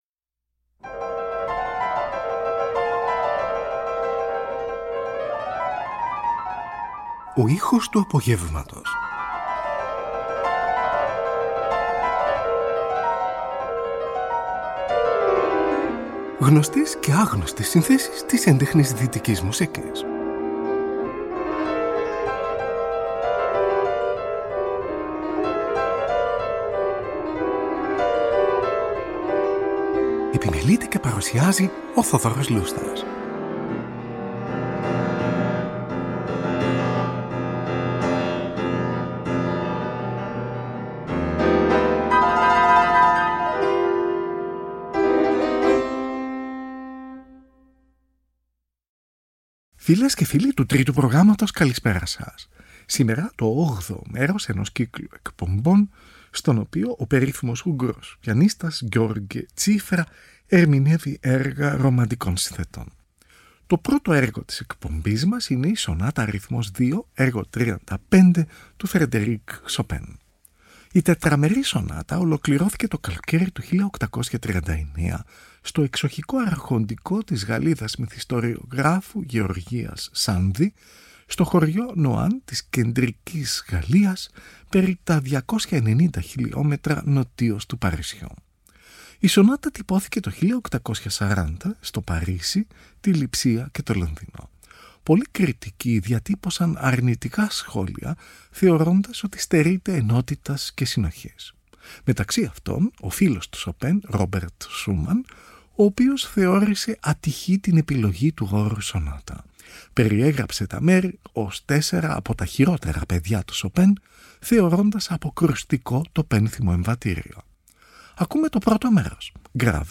O Ούγγρος Πιανίστας György Cziffra Ερμηνεύει Έργα Ρομαντικών Συνθετών – 8o Μέρος | Δευτέρα 20 Ιανουαρίου 2025